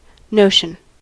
notion: Wikimedia Commons US English Pronunciations
En-us-notion.WAV